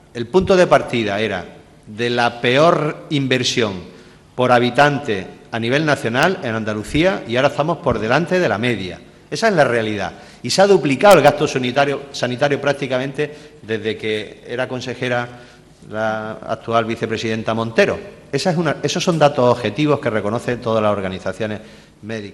Durante el Pleno celebrado hoy, Góngora ha recordado al PSOE que “no se está desmantelando el Sistema Sanitario Público Andaluz.